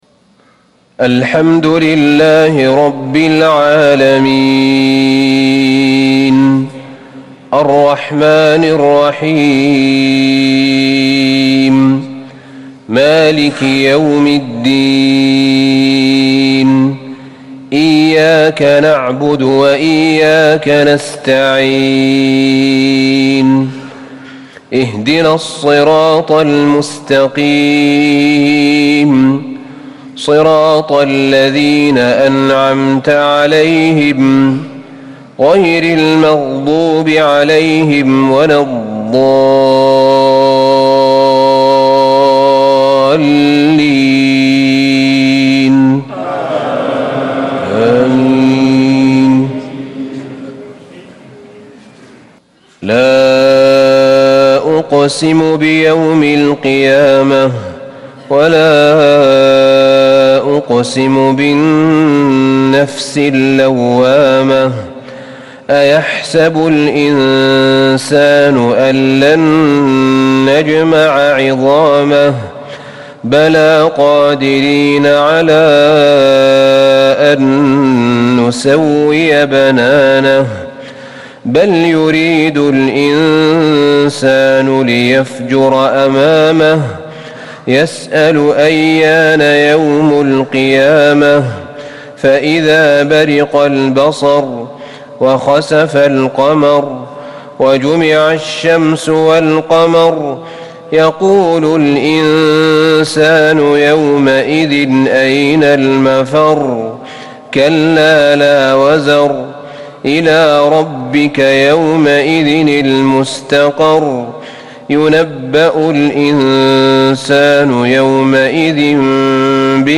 صلاة العشاء 2-6-1440هـ سورة القيامة | Isha 7-2-2019 prayer from Surat Al-Qiyamah > 1440 🕌 > الفروض - تلاوات الحرمين